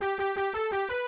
sunken_enemy.wav